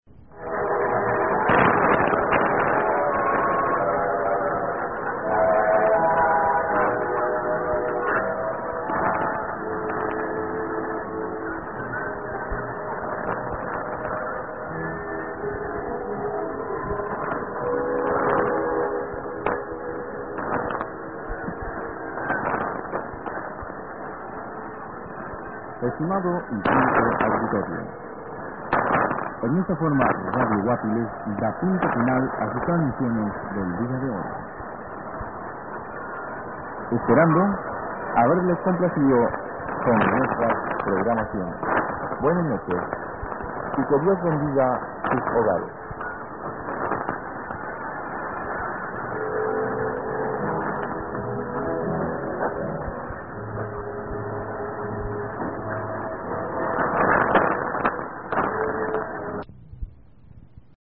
All recordings were made in Santa Barbara, Honduras (SB) using a Yaesu FRG-7 receiver.